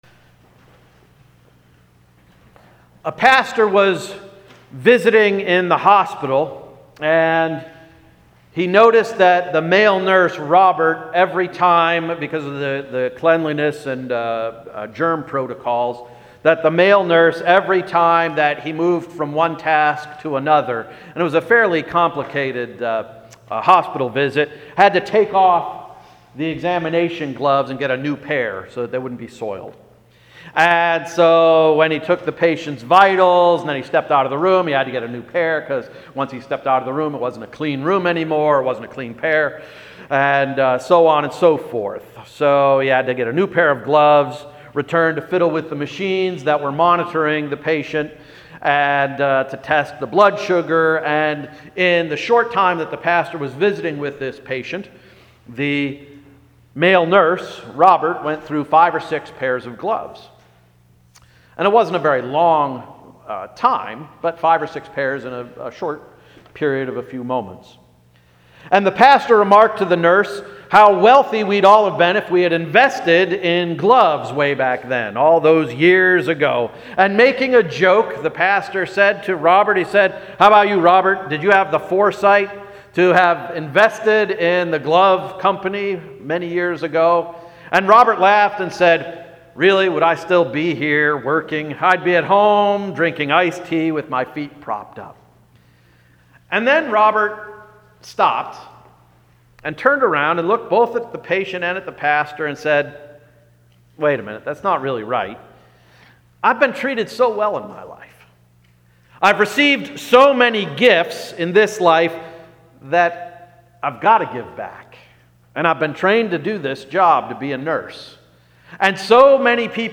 April 30 Sermon — “Is that you, Jesus?”